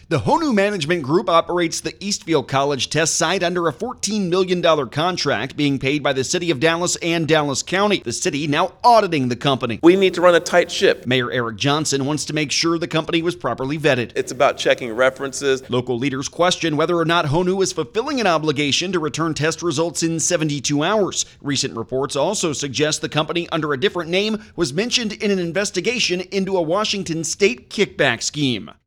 DALLAS (WBAP/KLIF) – The company running one of the largest COVID-19 testing sites in the metroplex is now under the microscope.
“We have to run a tight ship,” said Dallas Mayor Eric Johnson.